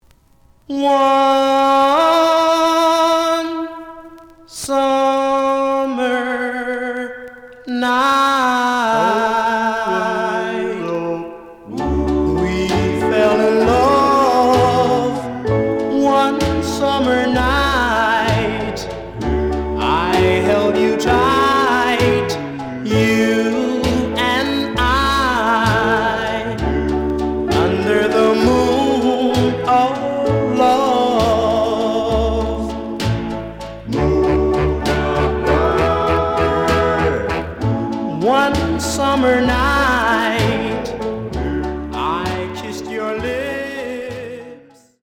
試聴は実際のレコードから録音しています。
●Genre: Rhythm And Blues / Rock 'n' Roll
●Record Grading: VG+~EX- (両面のラベルにダメージ。多少の傷はあるが、おおむね良好。)